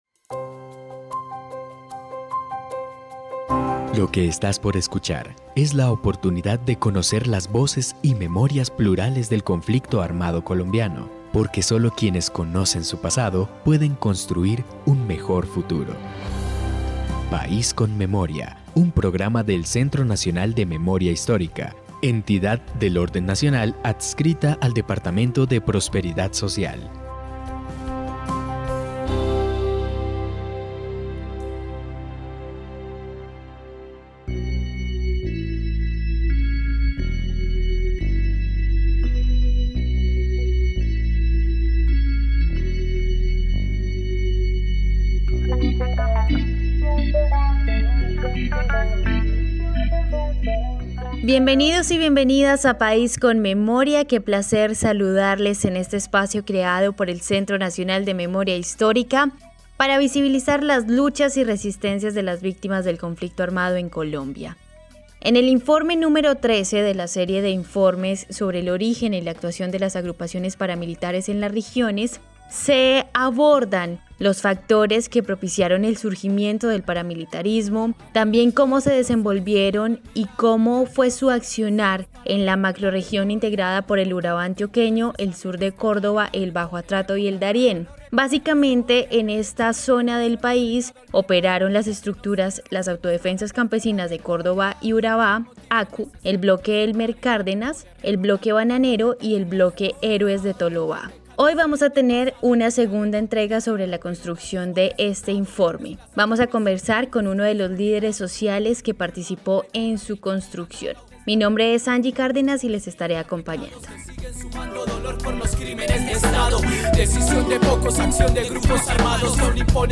Descripción (dcterms:description) Capítulo número 19 de la cuarta temporada de la serie radial "País con Memoria".